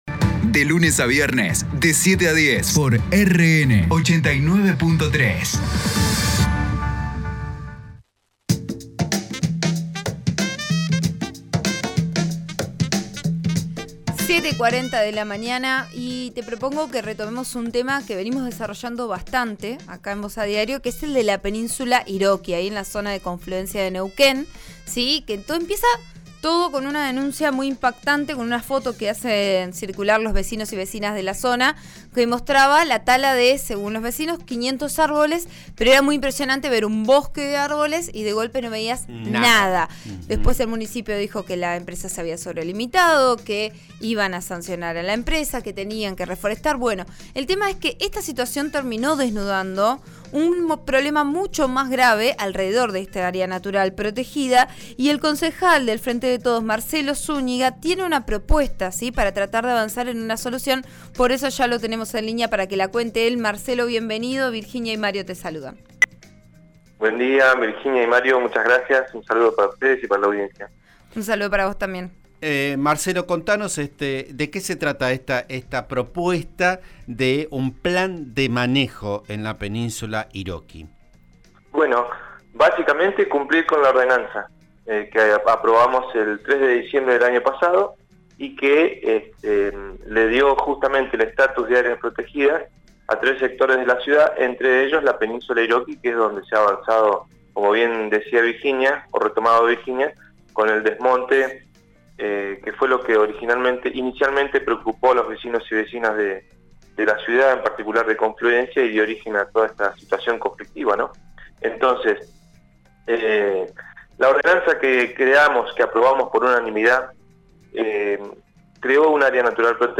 Escuchá su propuesta en la nota que dio a «Vos A Diario» (RN RADIO 89.3):